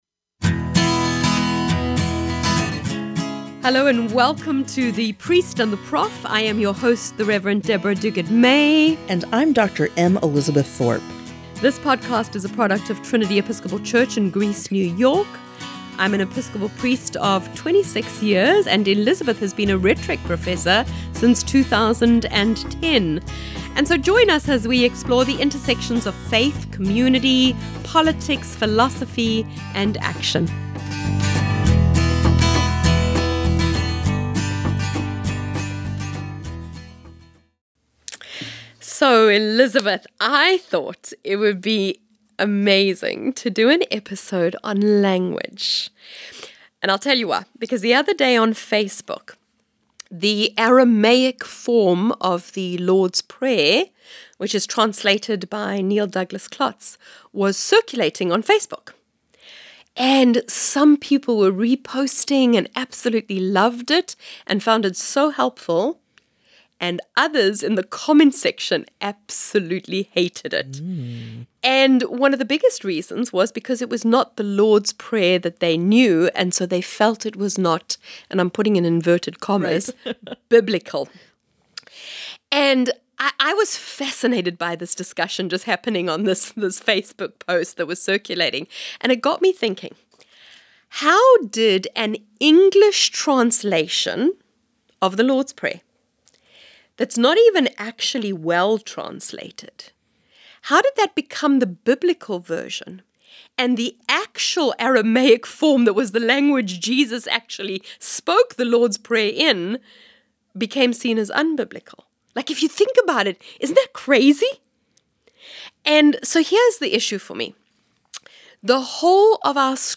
Full Interview - Ep#088 49:34